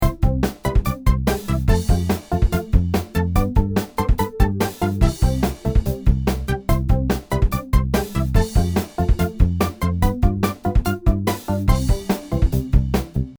편곡 연습